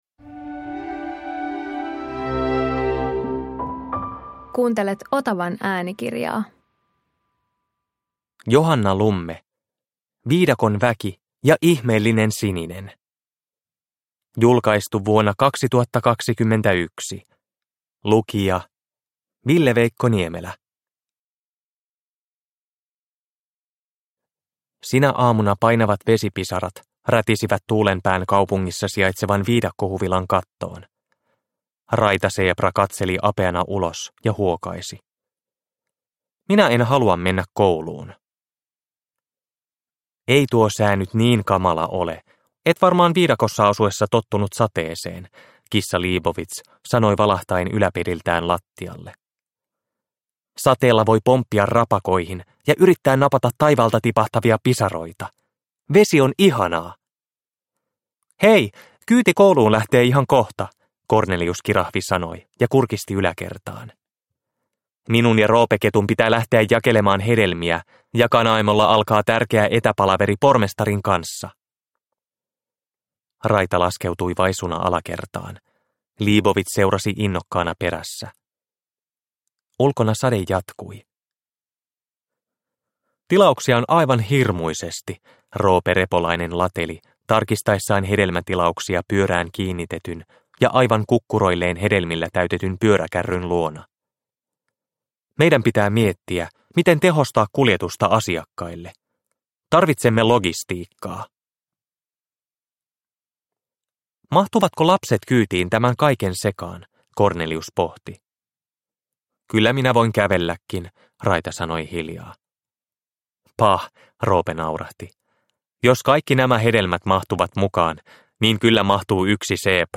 Viidakon väki ja ihmeellinen sininen – Ljudbok – Laddas ner